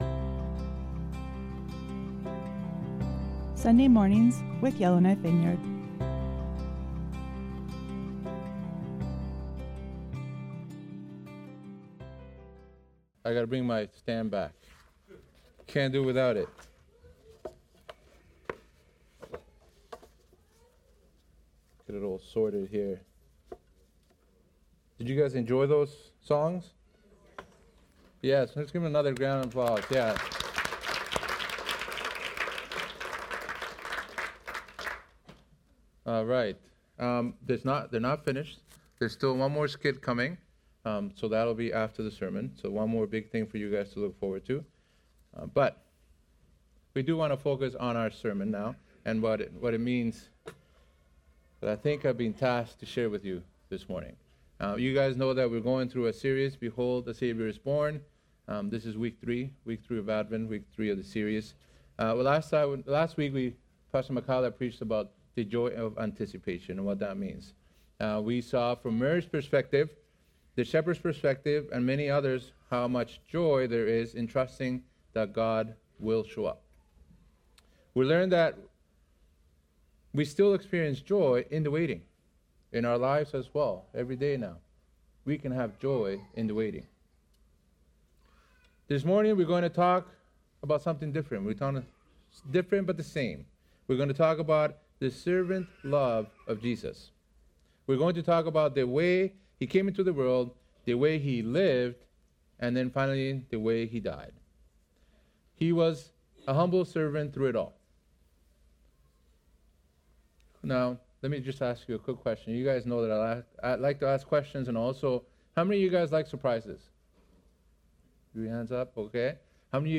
Sermons | Yellowknife Vineyard Christian Fellowship
Guest Speaker